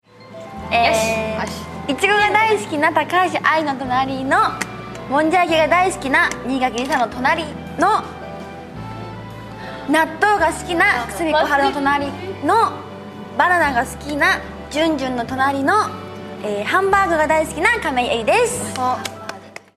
nagai_jiko_shoukai_momusu_2.mp3